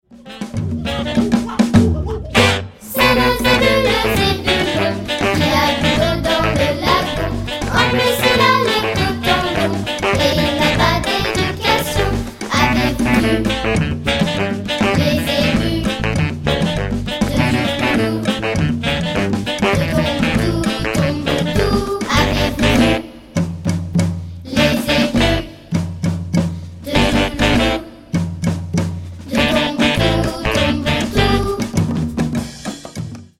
Une chanson cycle 2 qui permet d’introduire des petites percussions pour lui donner un style afro-cubain.